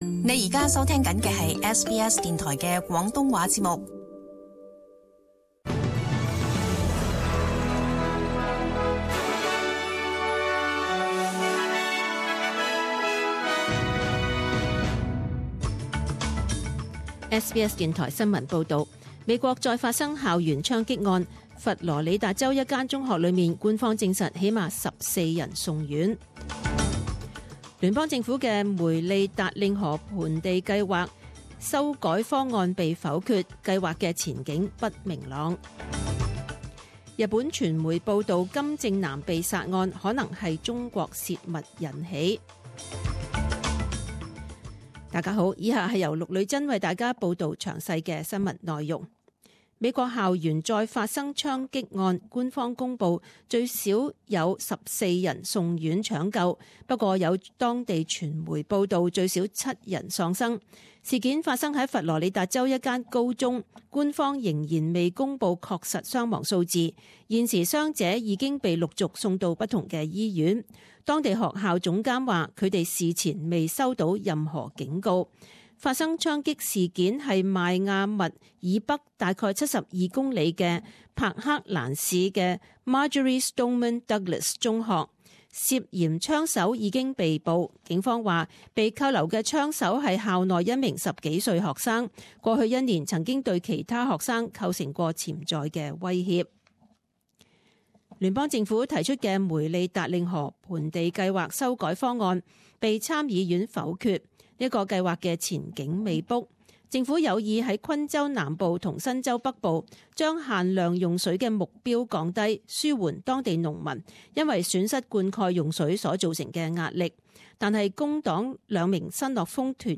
[十點鐘新聞] 二月十五日